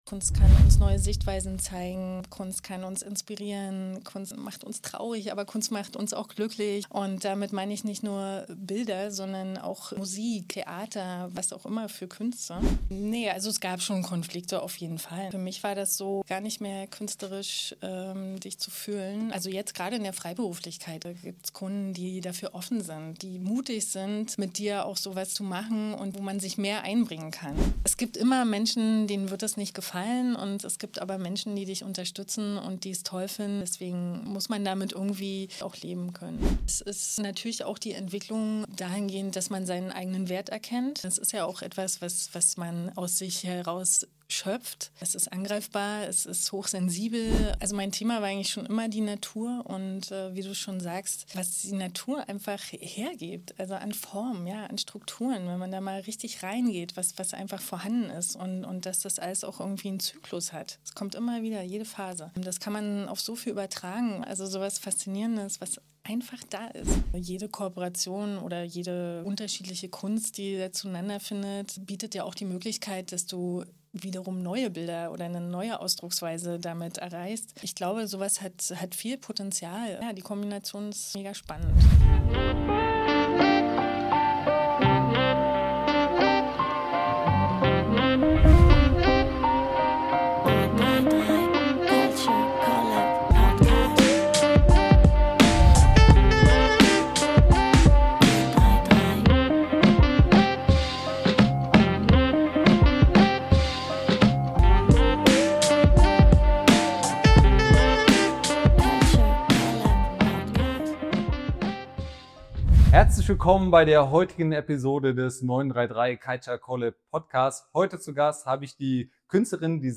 Diese Folge ist ein Gespräch über kreative Disziplinen im Wandel der Zeit.